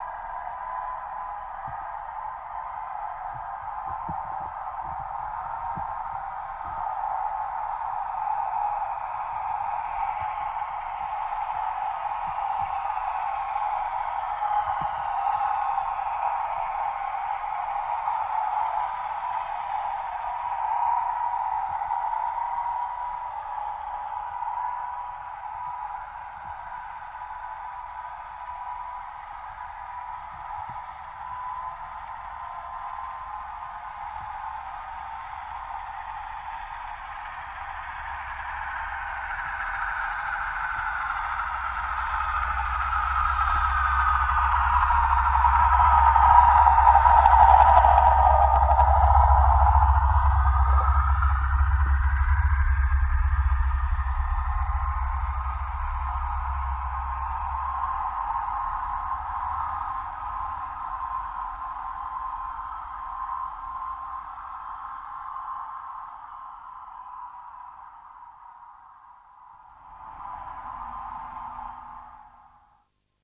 Torpedo Jet By